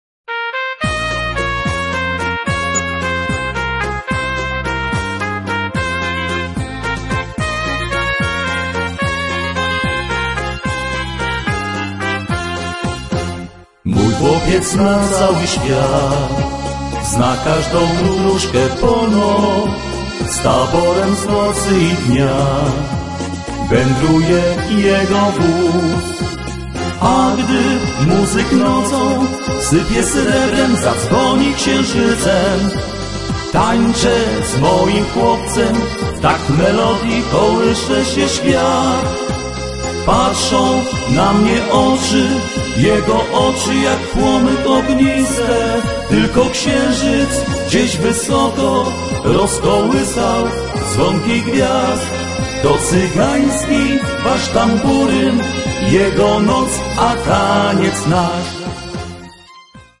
Vocals
Accordion
Percussion
Guitars